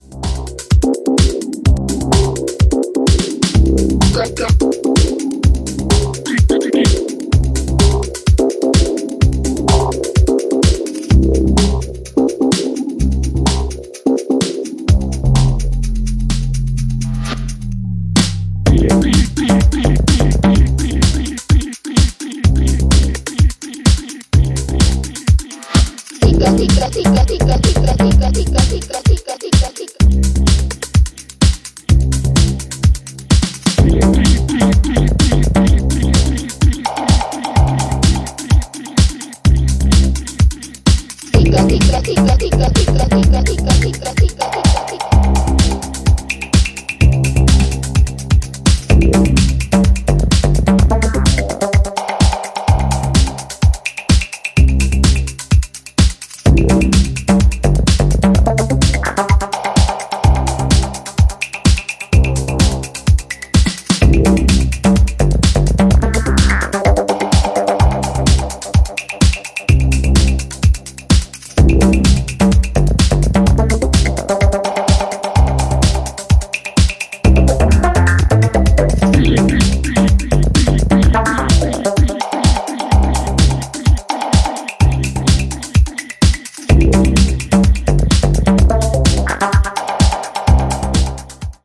ジャンル(スタイル) HOUSE / TECHNO